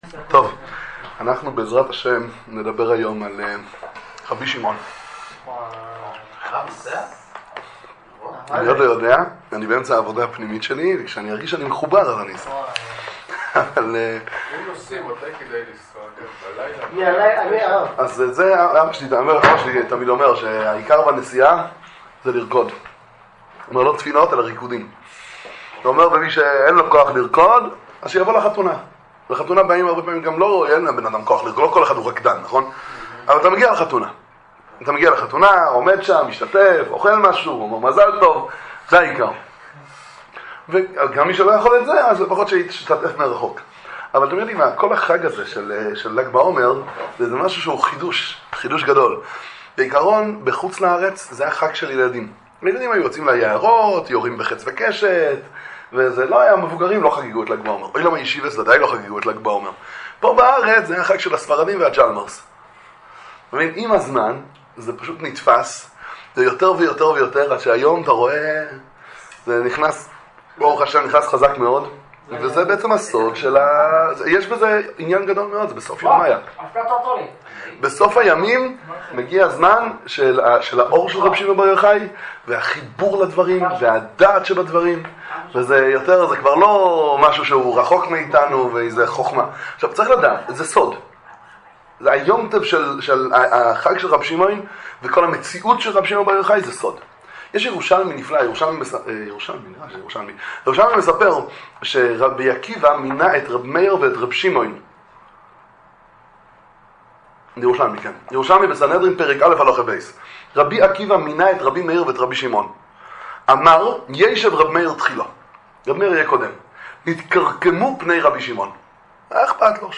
דבר תורה ל"ג בעומר, שיעור על גדולת רשב"י, שיעורי תורה בענין רבי שמעון בר יוחאי